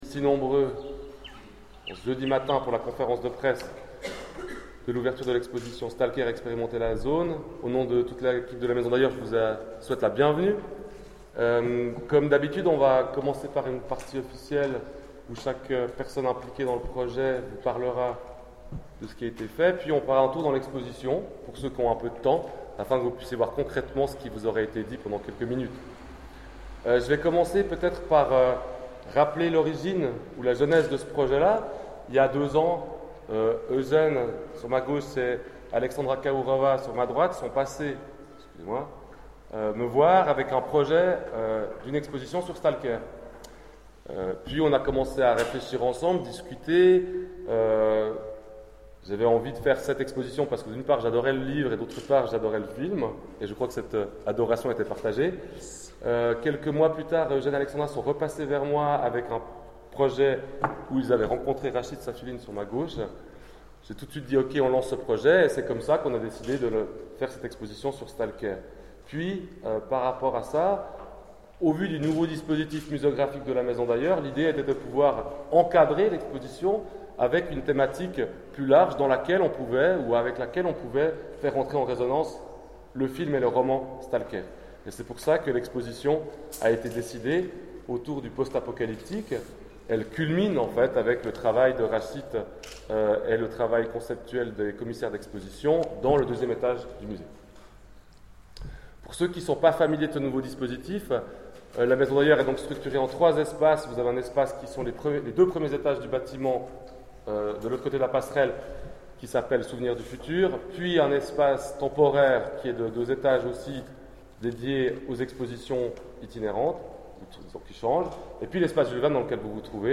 Conférence de presse de l'exposition Stalker